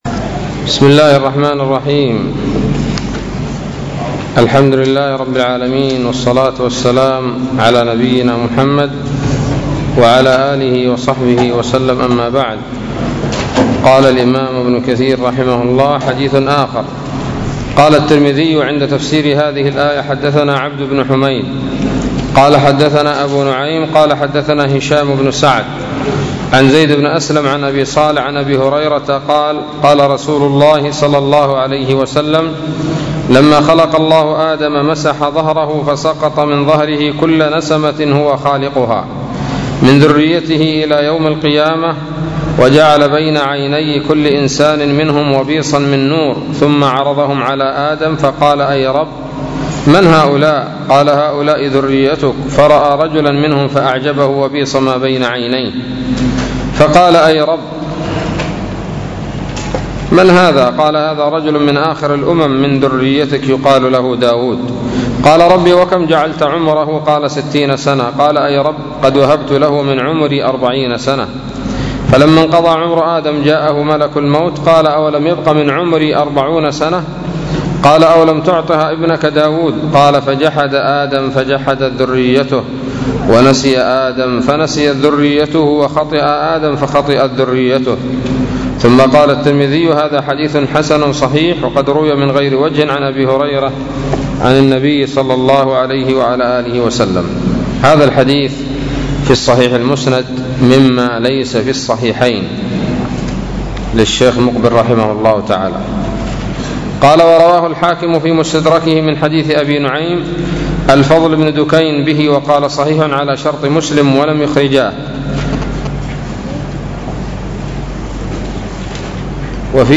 الدرس الثالث والستون من سورة الأعراف من تفسير ابن كثير رحمه الله تعالى